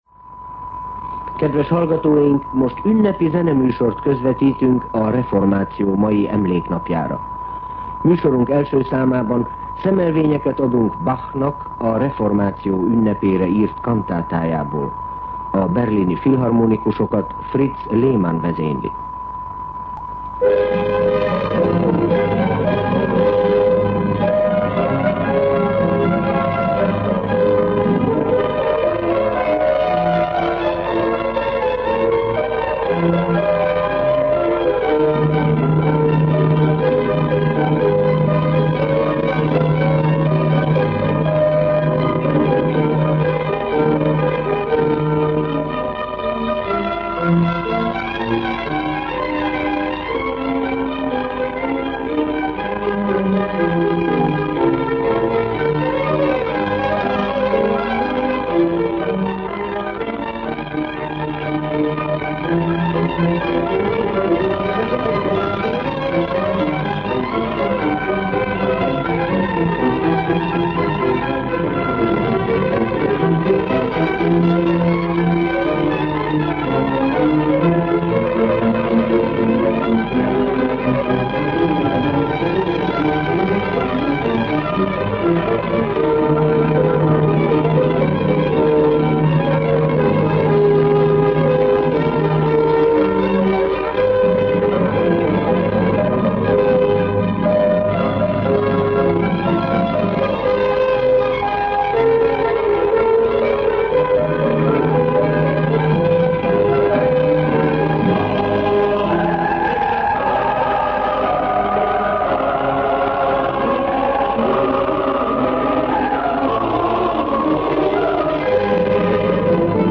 Most ünnepi zeneműsort közvetítünk a reformáció mai emléknapjára.
A Berlini Filharmonikusokat Fritz Lehmann vezényli. zene Két részletet hallottak Bach reformációs kantátjából.
A Los Angeles-i Filharmonikus Zenekart Alfred Wallenstein vezényelte.